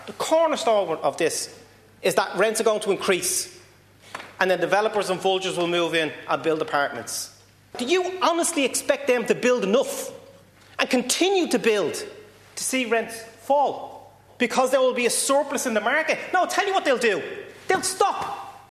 Sinn Féin TD Paul Donnelly claims the Housing Minister’s belief that supply will increase doesn’t hold water…….….